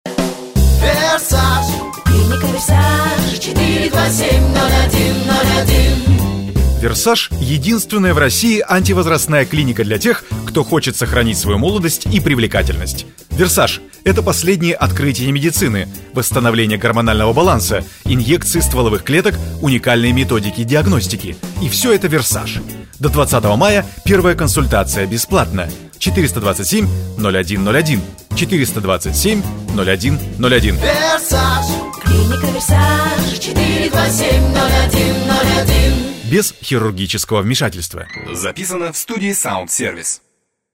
3) «Имиджевый» ролик, объектом рекламы в котором становятся не конкретные услуги или товар, а сам бренд, цель имиджевого ролика – позиционировать бренд на рынке, подчеркнуть престижность марки.